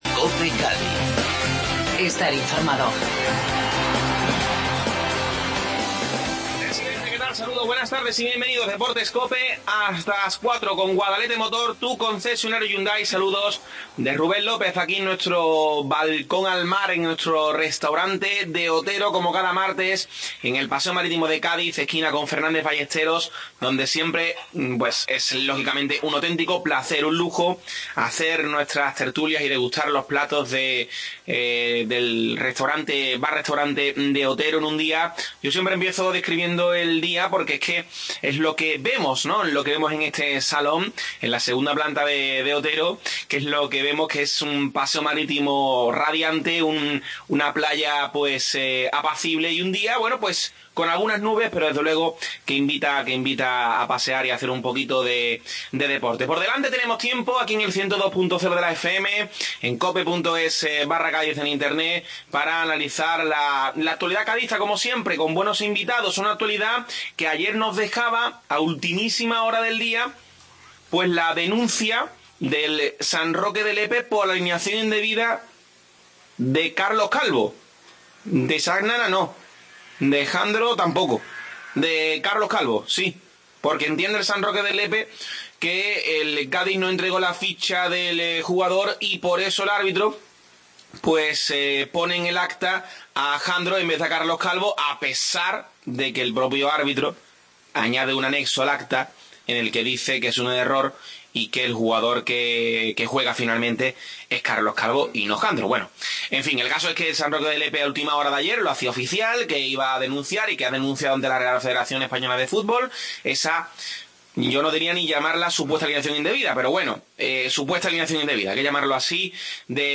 Desde el Restaurante De Otero tertulia